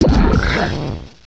sovereignx/sound/direct_sound_samples/cries/bellibolt.aif at eccd2c4a659cd472a3ea583b4d1ac750e60a59ce